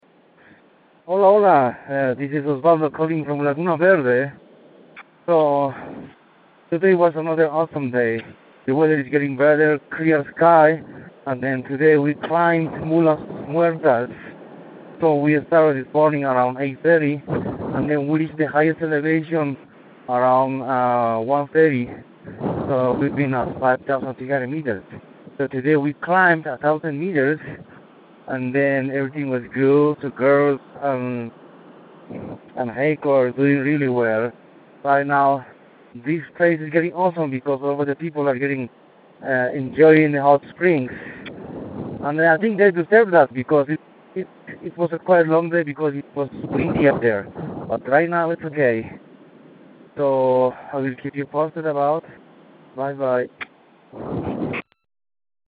Ojos del Salado Expedition Dispatch